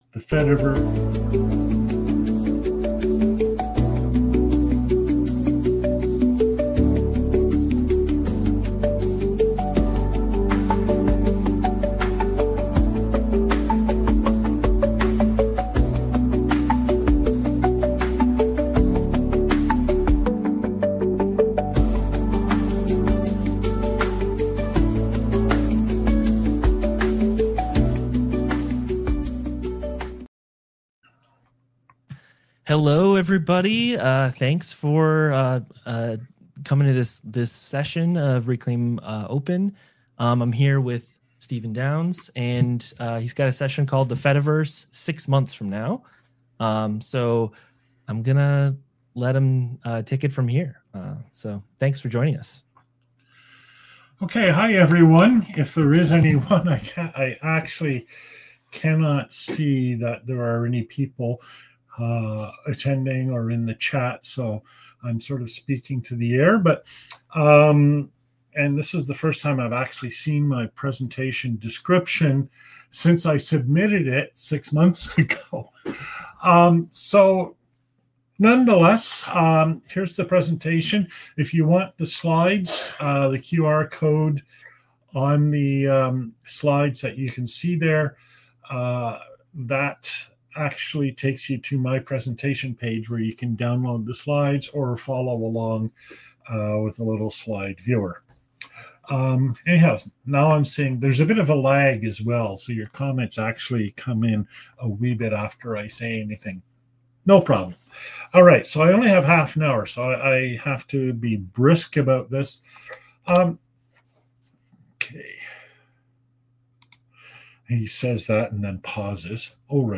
(Old style) [ Slides ] [ PDF ] [ Audio ] [ Video ] (New Style) [ PDF ] [ PPTx ] [ Audio ] [] Reclaim Open 2025, Reclaim Hosting, Online, via Streamyard, Lecture, Nov 05, 2025.